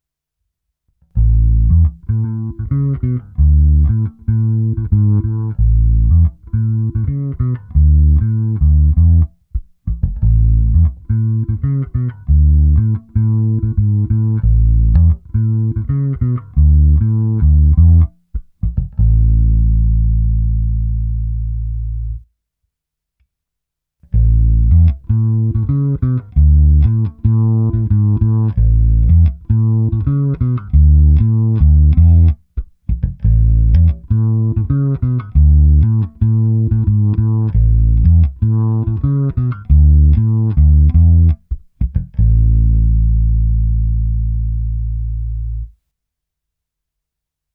Hráno vždy blízko krku.
Všechny korekce na 1/2 a prohnal jsem to přes Darkglass Harmonic Booster, TC Electronic SpetraComp a Microtubes X Ultra se zapnutou simulací aparátu.
Pro zajímavost, a mimochodem taky se mi ten zvuk líbil, jsem udělal to samé, ale s oběma snímači.